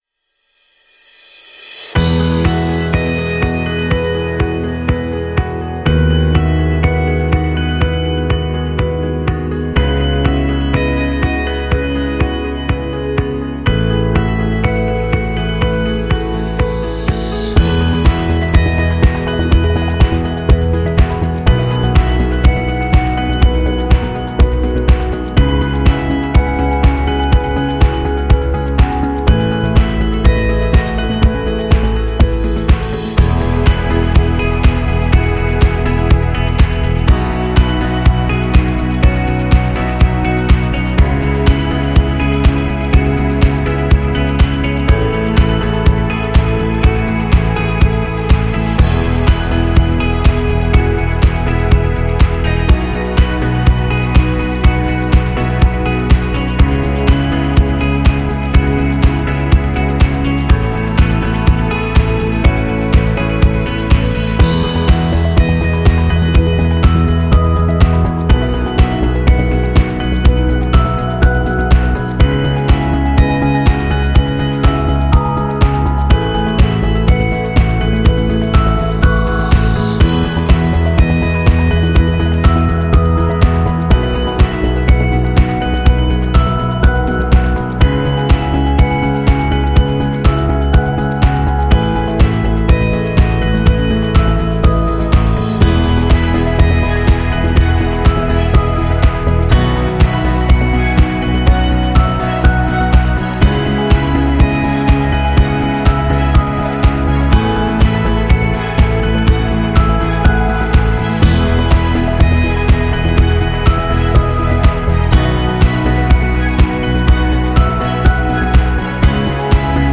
۴. کورپُریت/ تجاری (Corporate / Background Music)